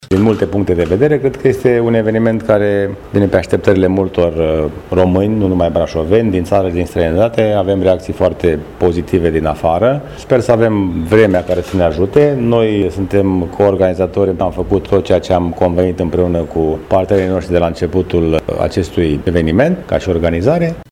Primarul Brașovului, George Scripcaru, a spus …